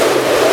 SURF.WAV